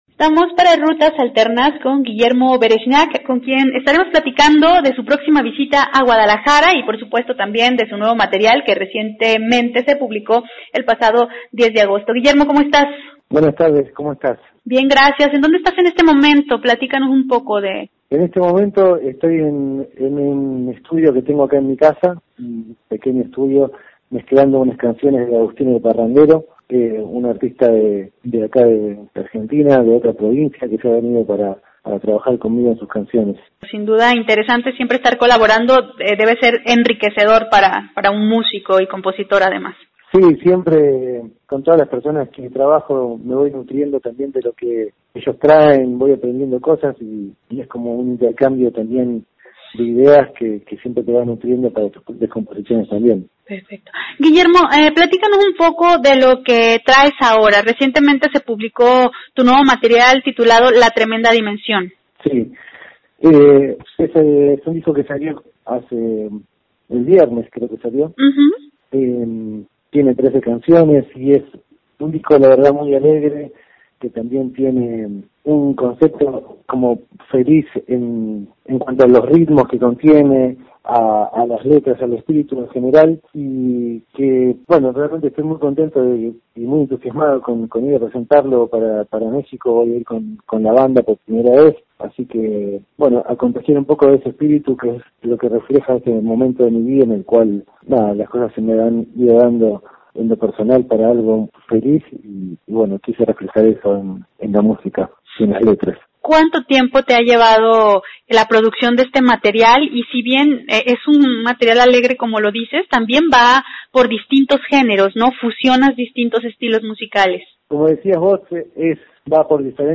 De esto y más nos habla en entrevista para Rutas Alternas.